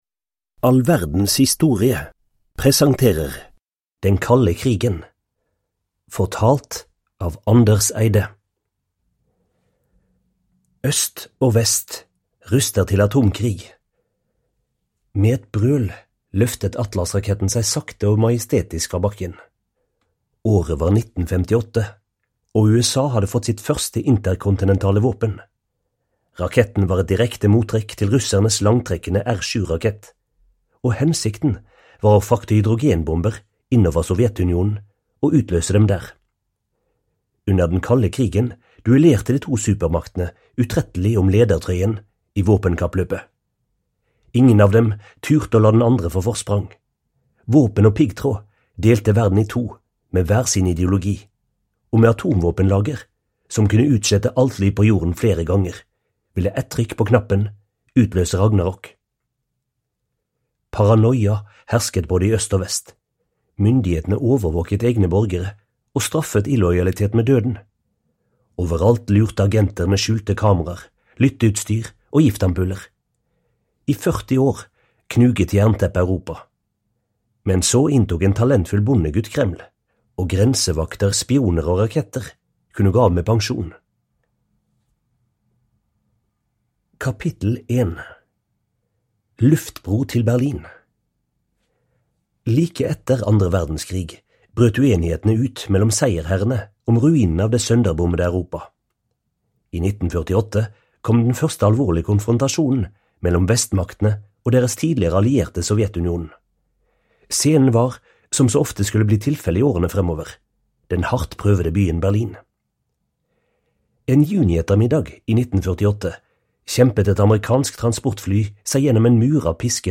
Den kalde krigen (ljudbok) av All verdens historie